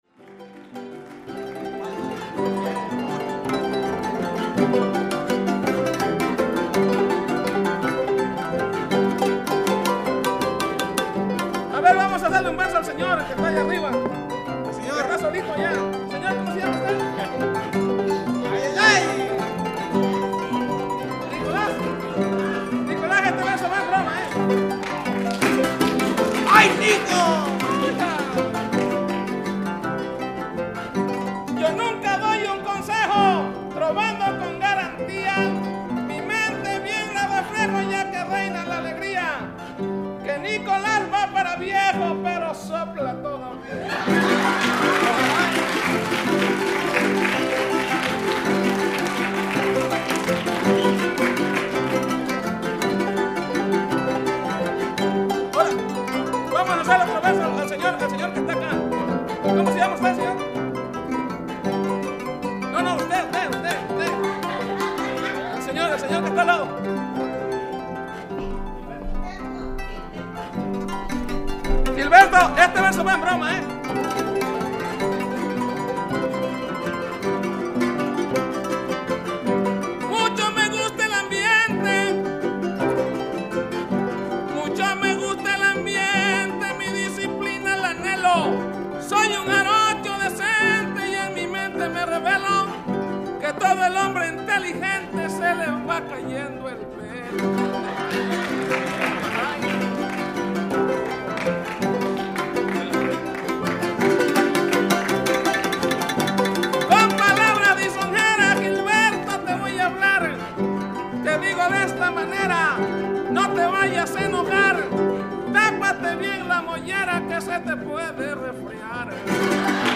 MEXICAN   Mariachi music in East Los Angeles; Jarocho in San Diego